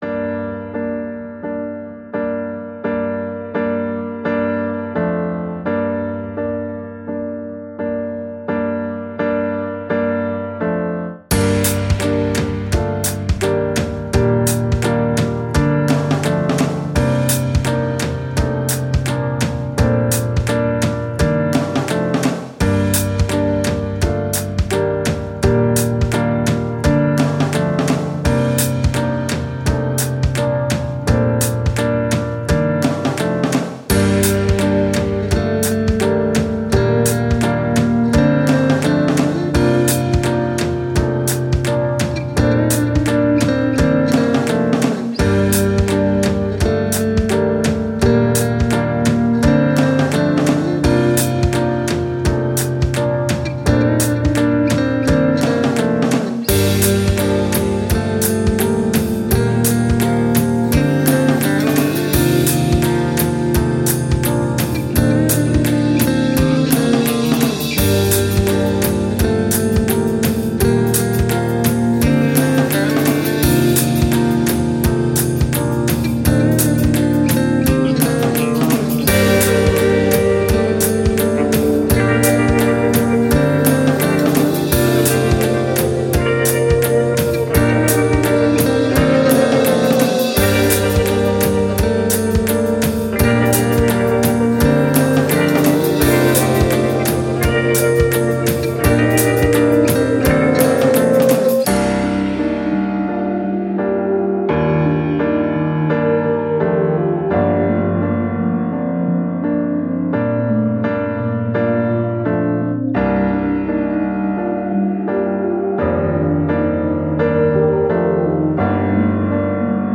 Ambient, Downtempo, Pensive, Thoughtful